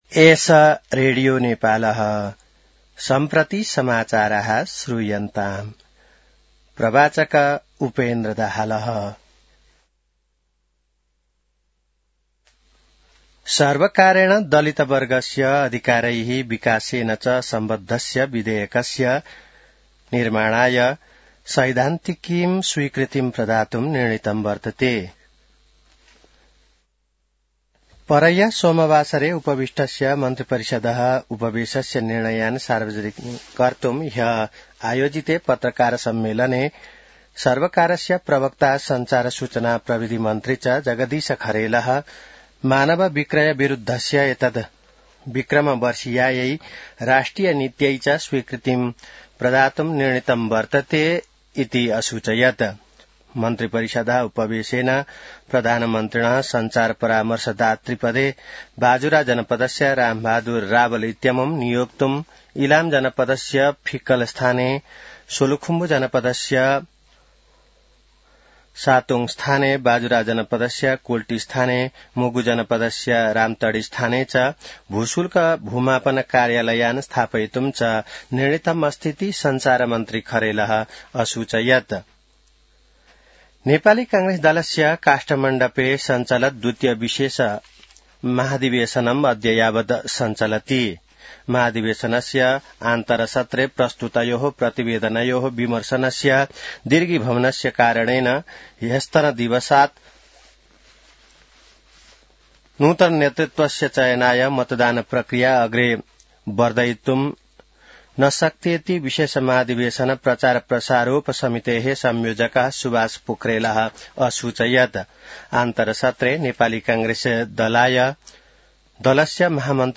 An online outlet of Nepal's national radio broadcaster
संस्कृत समाचार : ३० पुष , २०८२